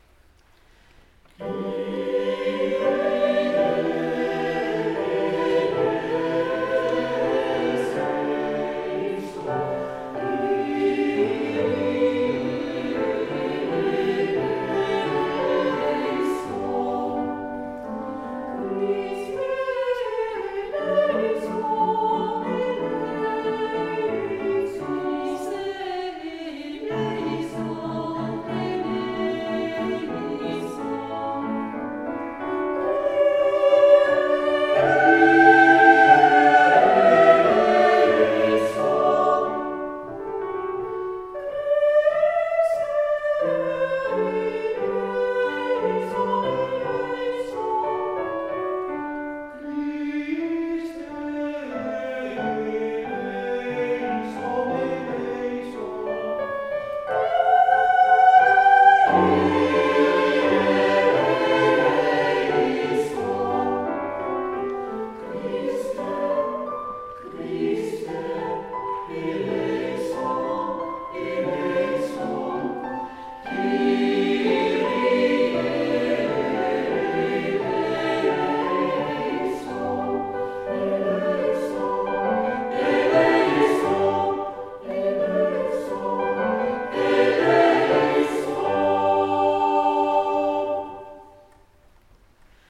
31 oktober 2021 Weezenkapel-van ’t Lindenhoutmuseum,
Piano